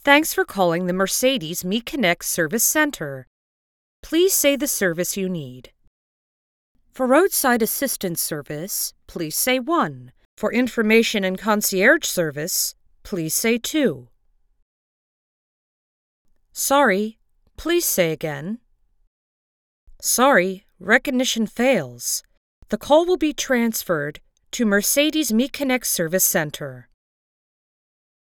Female
English (North American)
Adult (30-50)
Phone Greetings / On Hold
All our voice actors have professional broadcast quality recording studios.
0527Merceded_Me_Connect_IVR.mp3